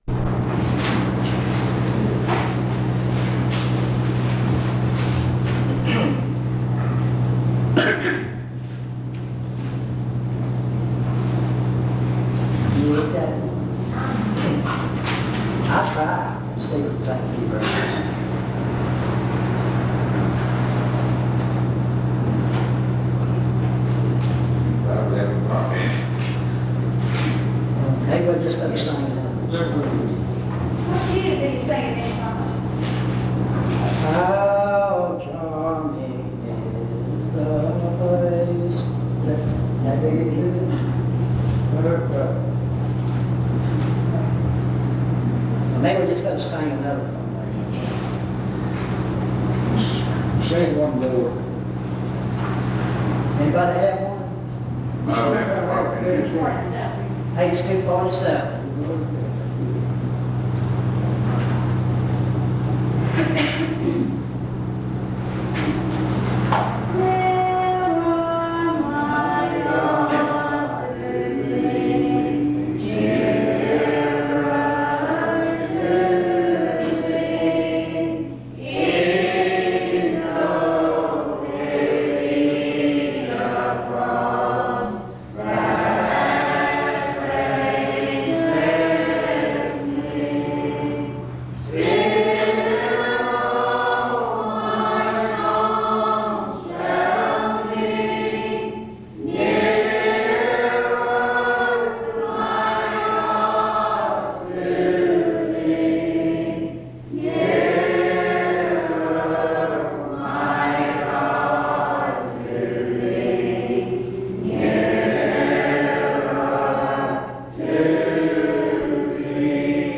In Collection: Reidsville/Lindsey Street Primitive Baptist Church audio recordings Thumbnail Title Date Uploaded Visibility Actions PBHLA-ACC.001_024-B-01.wav 2026-02-12 Download PBHLA-ACC.001_024-A-01.wav 2026-02-12 Download